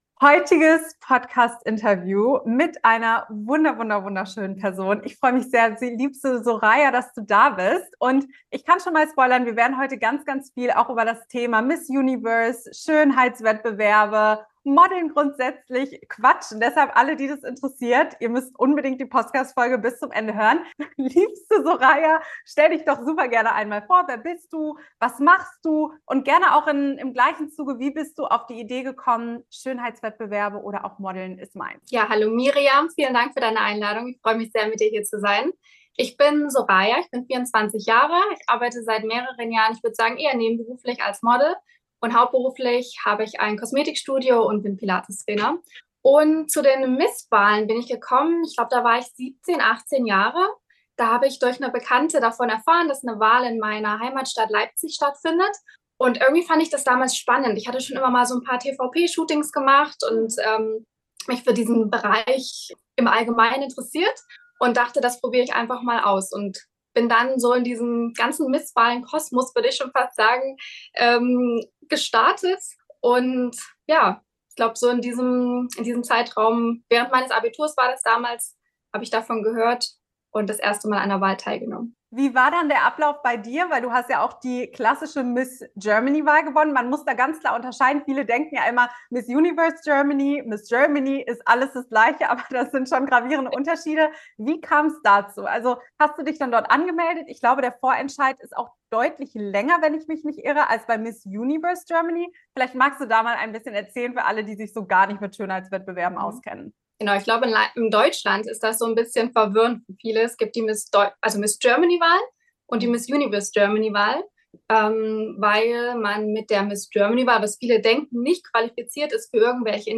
#157: Sind Miss Wahlen oberflächlich? - Ein Interview